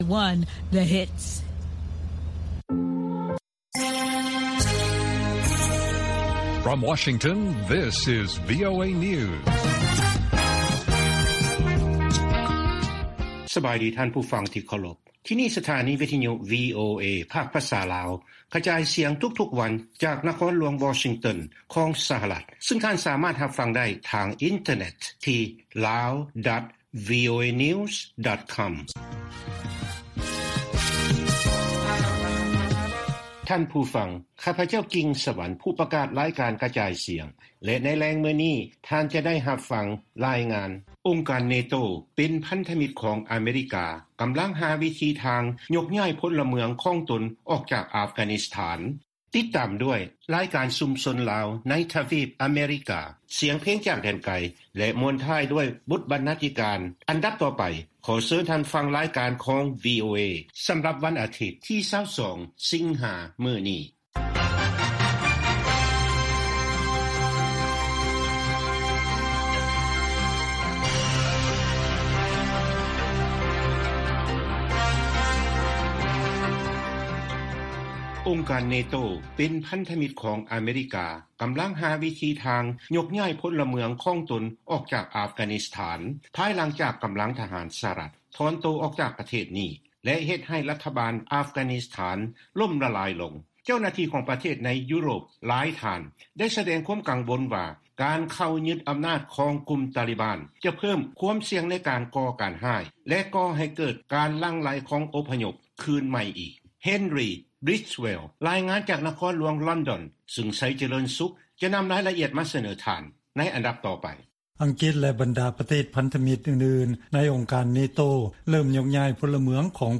ວີໂອເອພາກພາສາລາວ ກະຈາຍສຽງທຸກໆວັນ.